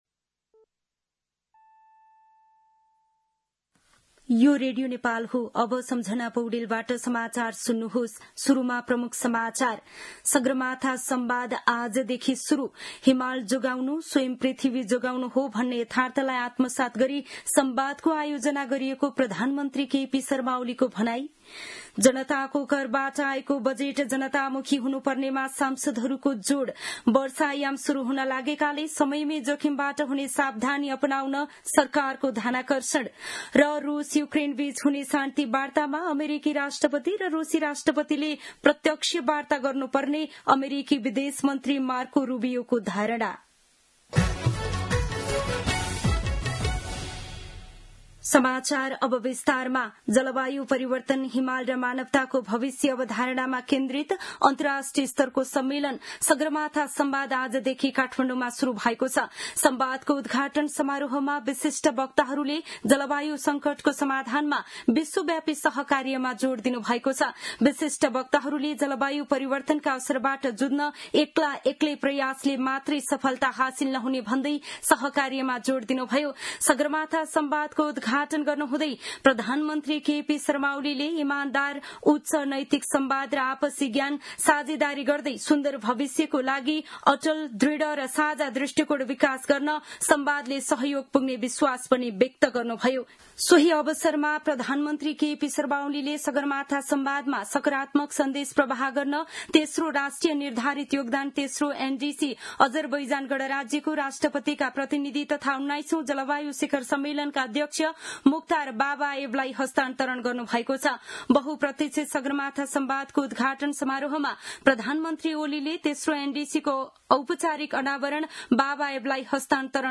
दिउँसो ३ बजेको नेपाली समाचार : २ जेठ , २०८२
3pm-News-02-2.mp3